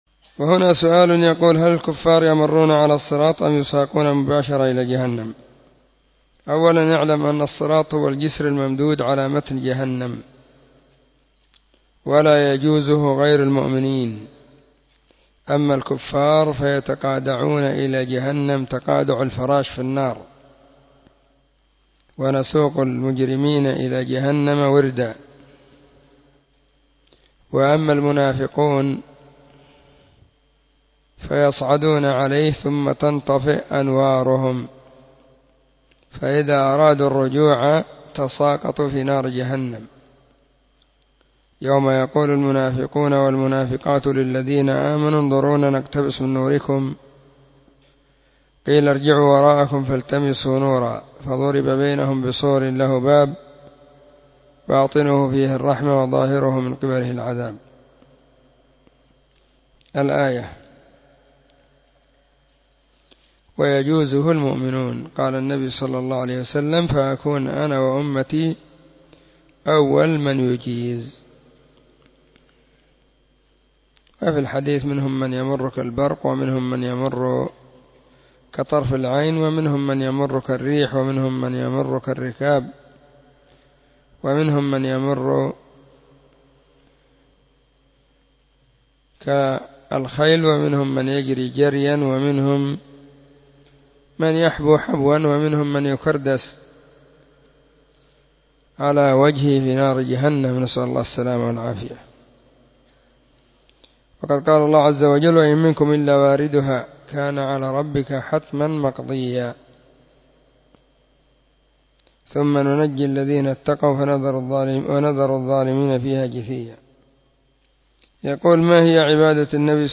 🔹 سلسلة الفتاوى الصوتية 🔸الاربعاء 3 /محرم/ 1442 هجرية. ⭕ أسئلة ⭕ -4